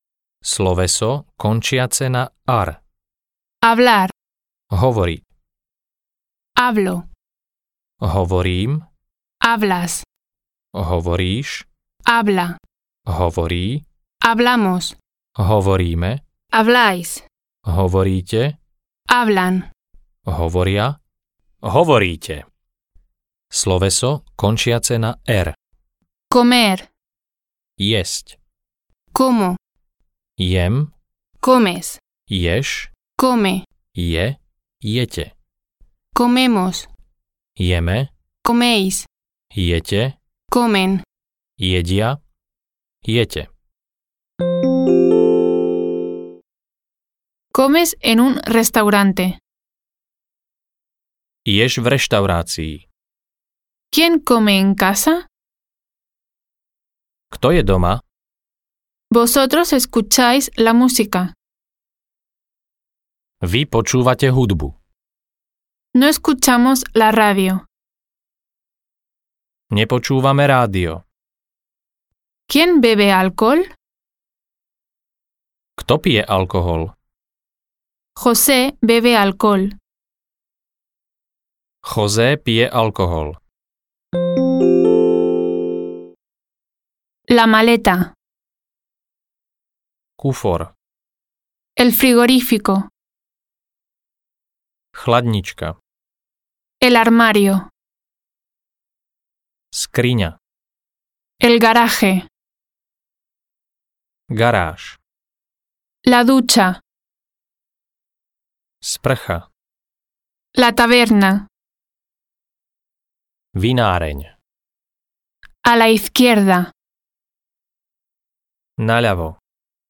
Španielčina do ucha audiokniha